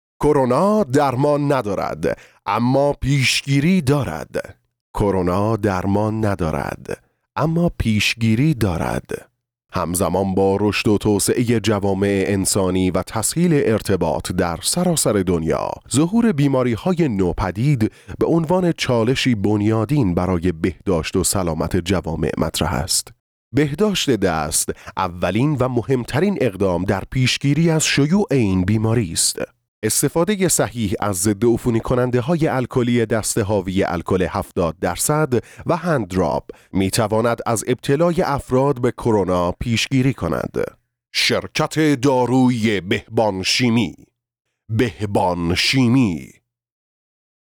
Narration
Male
Adult